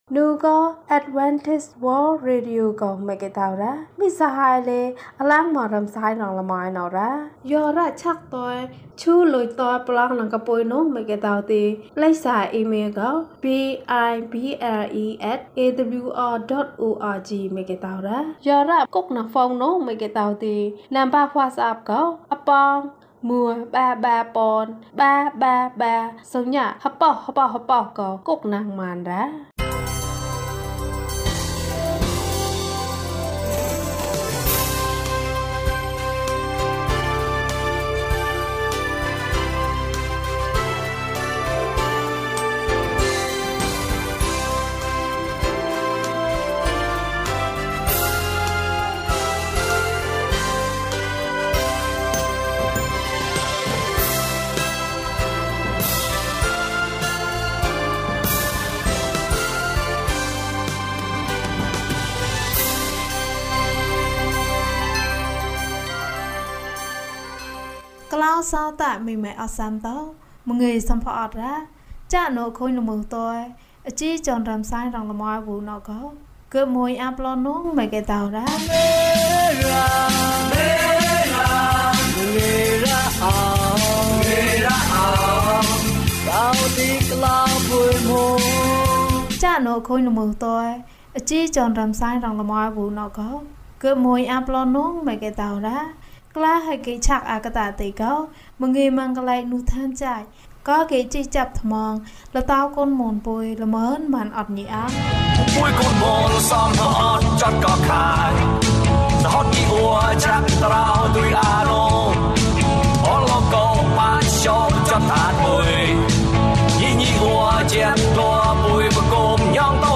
ခရစ်တော်ထံသို့ ခြေလှမ်း။၅၉ ကျန်းမာခြင်းအကြောင်းအရာ။ ဓမ္မသီချင်း။ တရားဒေသနာ။